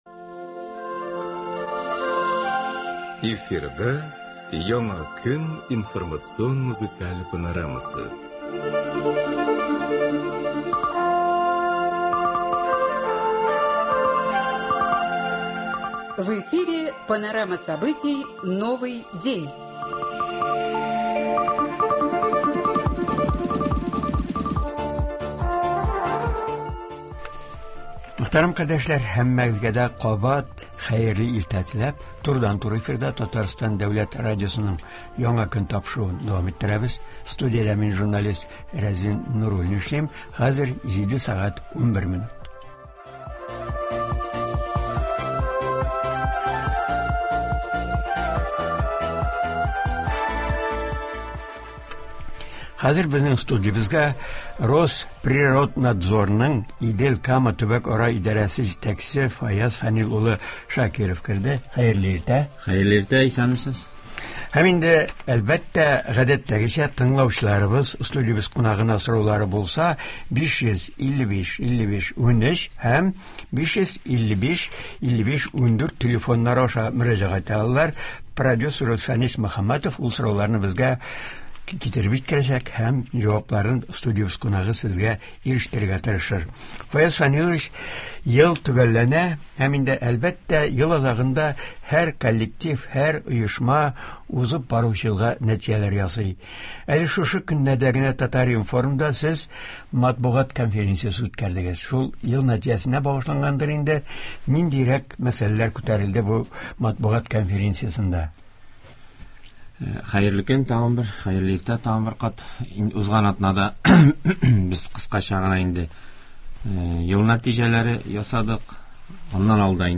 «Роспироднадзор» ның Идел-Кама төбәкара идарәсен җитәкләүче Фаяз Шакиров турыдан-туры эфирда катнашып, Татарстан экологлары эшенә бәя бирәчәк, тыңлаучыларыбыз сорауларына җавап бирәчәк.